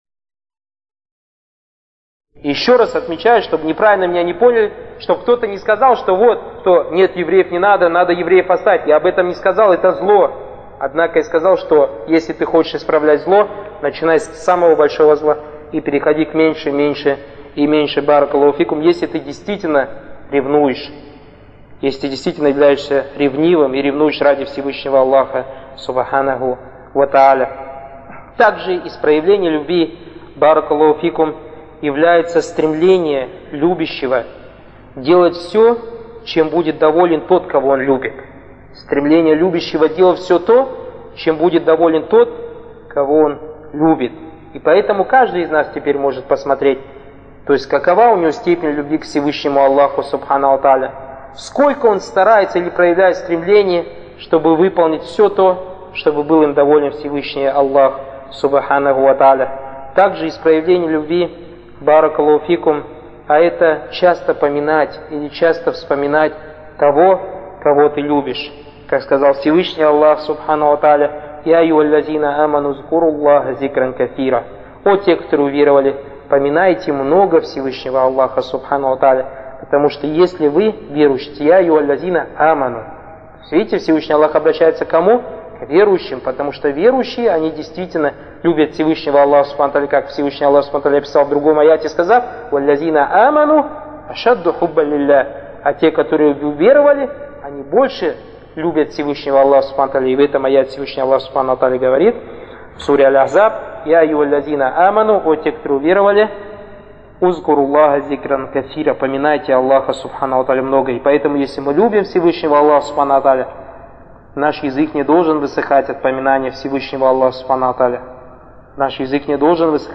Материал: лекции